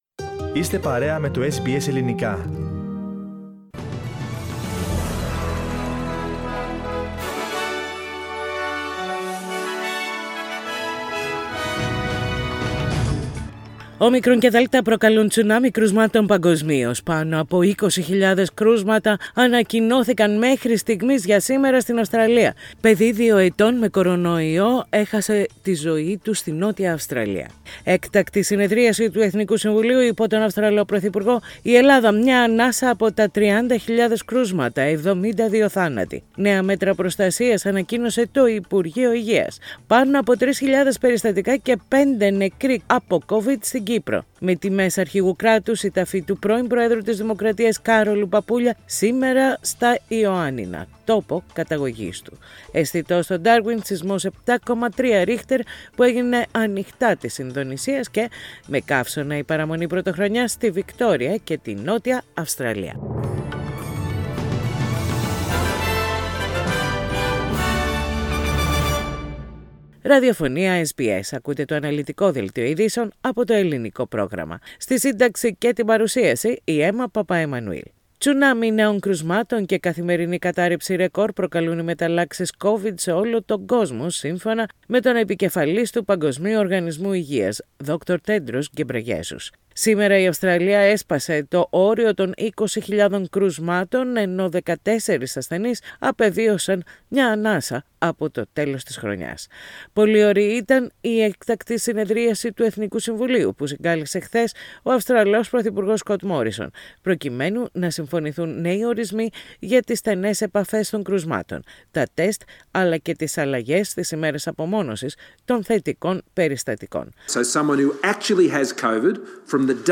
The detailed bulletin of the day with the main news from Australia, Greece, Cyprus and internationally.